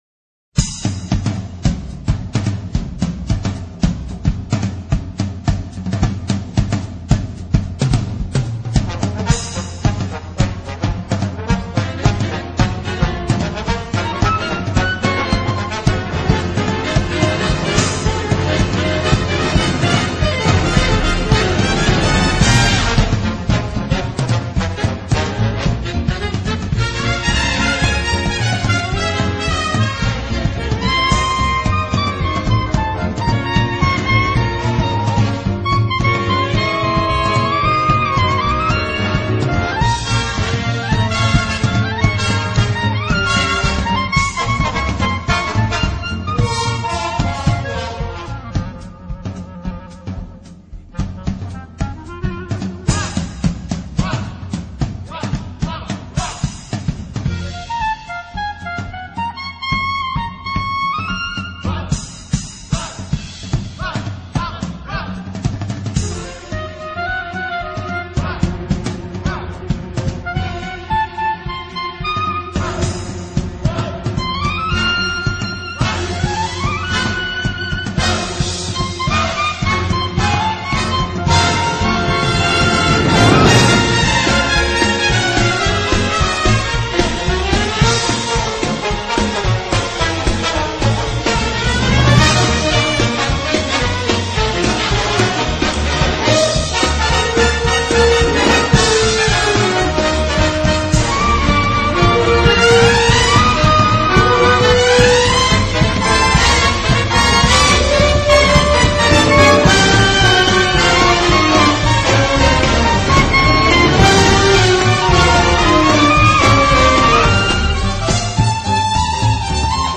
A quick mash-up